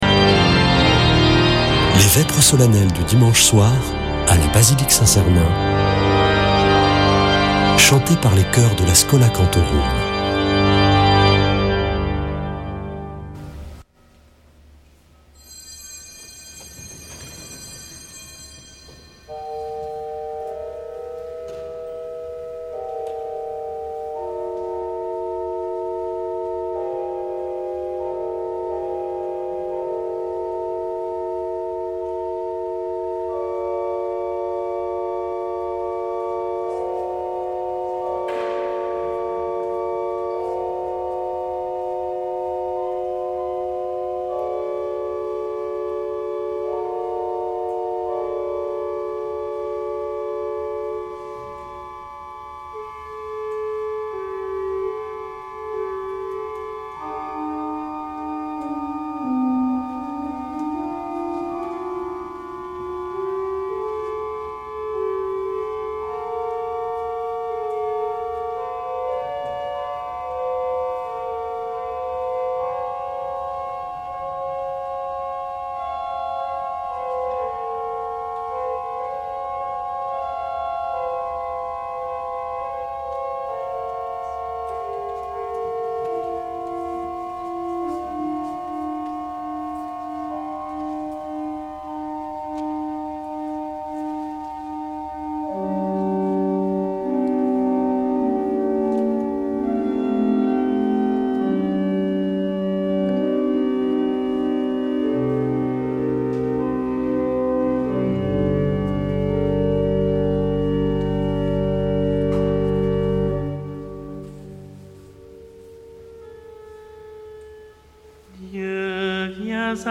Vêpres de Saint Sernin du 18 janv.